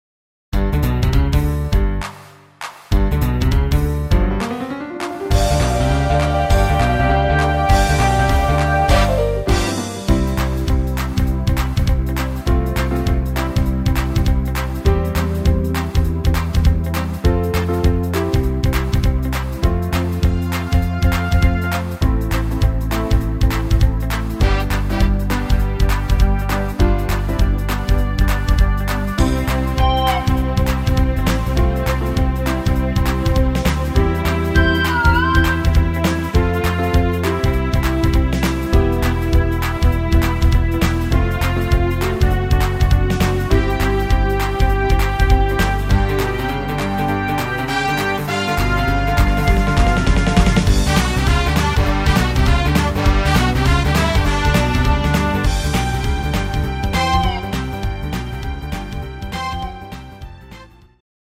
Rhythmus  Jive
Art  Schlager 90er, Deutsch, Weibliche Interpreten